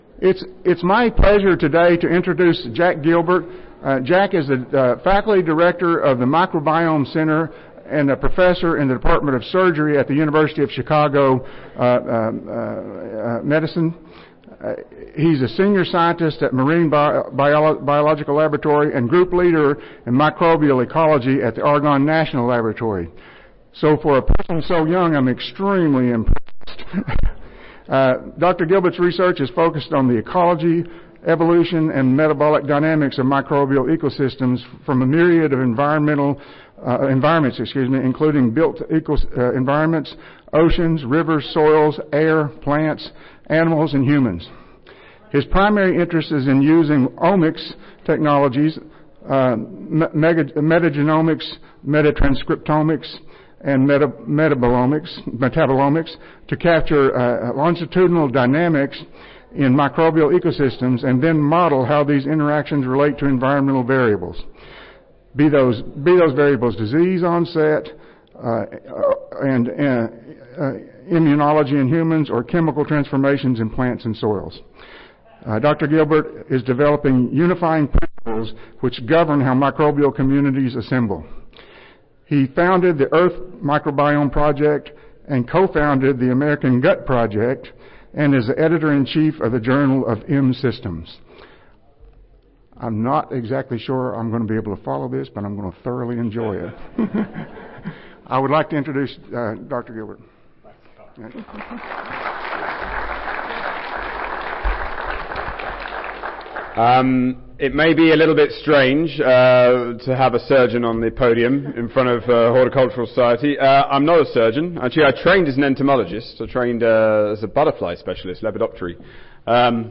Keynote Address
University of Chicago Audio File Recorded Presentation See more of